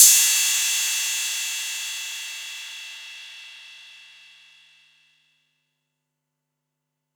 Crashes & Cymbals
Ride 888 1.wav